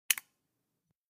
keypress.vPVpEePz.aac